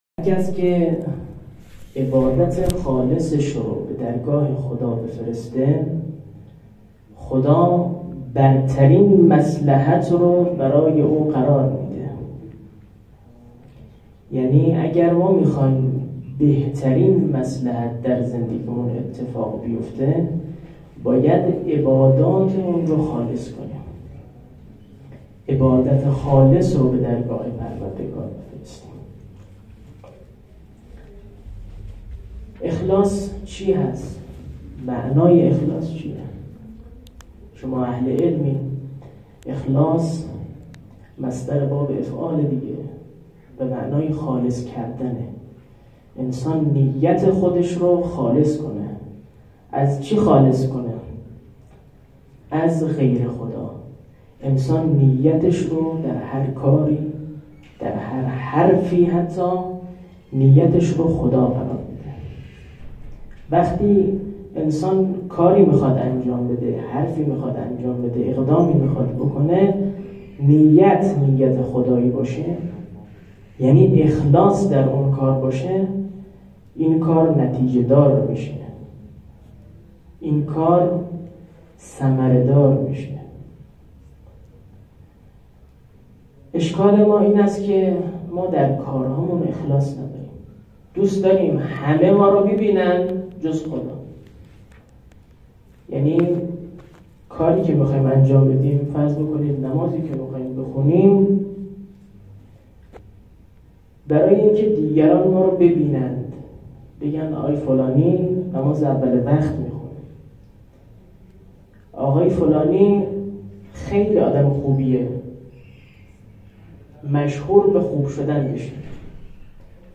سخنرانی هیئت هفتگی حوزه علمیه محمدیه شیراز .mp3
سخنرانی-هیئت-هفتگی-حوزه-علمیه-محمدیه-شیراز.mp3